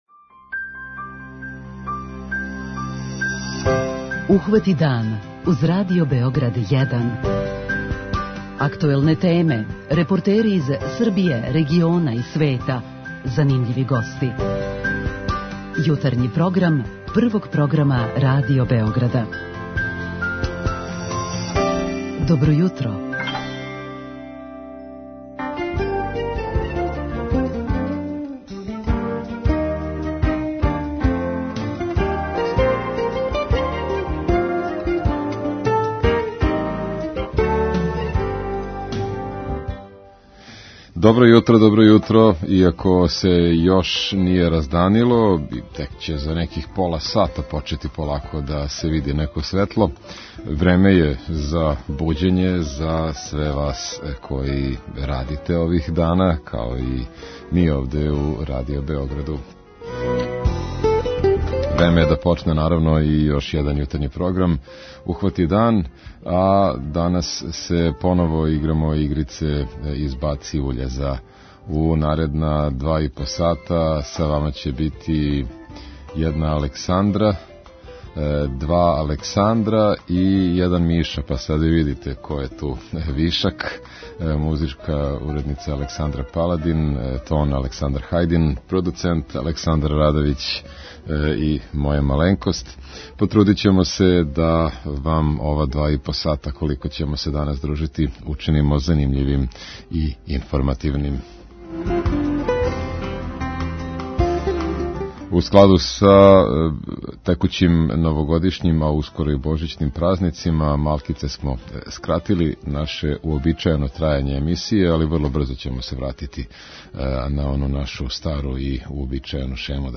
Да ли смо били толико заокупљени свакодневним бригама и преживљавањем 'од првог до првог' да смо заборавили на топлу људску реч и помоћ онима којима је то потребно? О овој теми разговараћемо са слушаоцима у нашој редовној рубрици 'Питање јутра'.